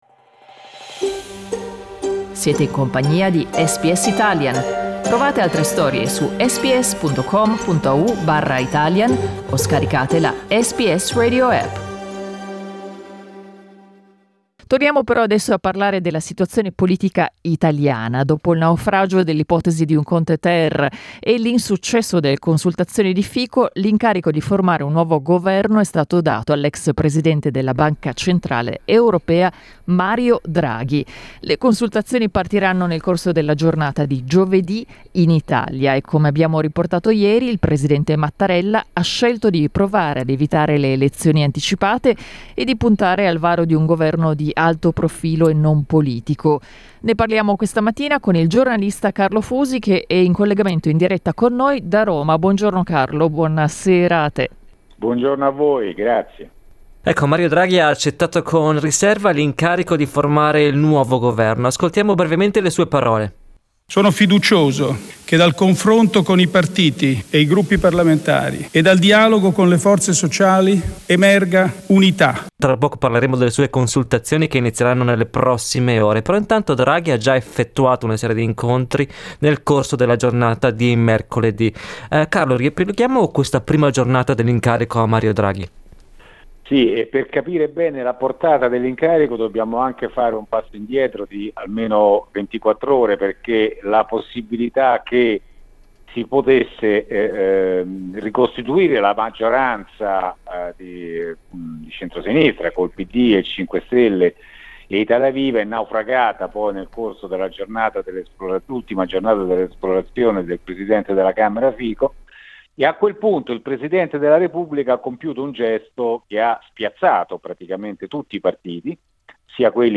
La nomina di Mario Draghi è stata "una specie di shock" ha detto a SBS Italian il giornalista